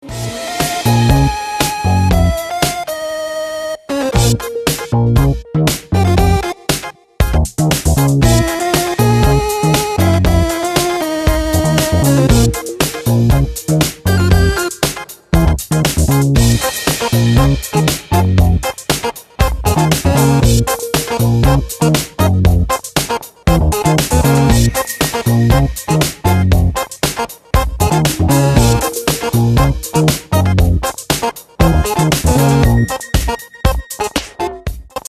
He makes freaked out Italo Disco that’s ahead of its time.